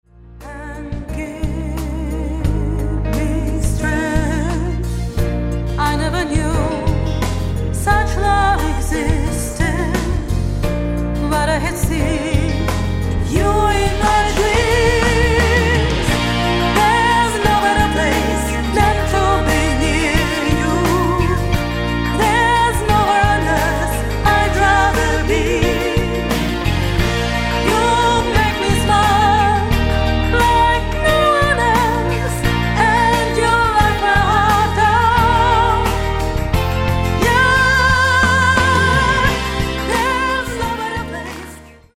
Bass
Drums
Guitar
Keyboards
Backup vocals
Mastered at Abbey Road Studios, London